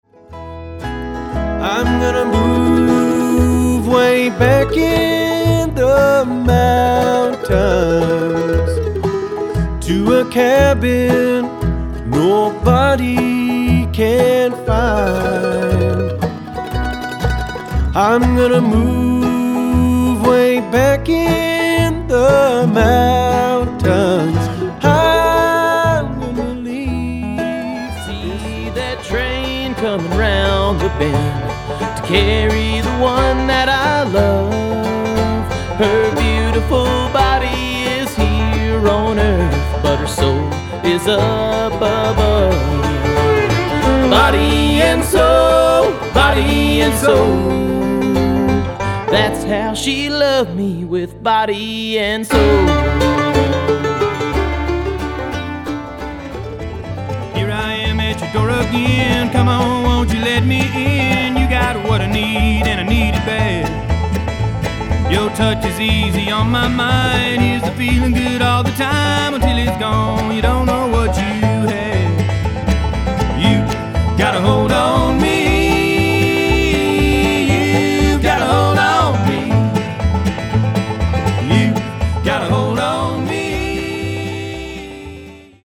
Genre/Style: Bluegrass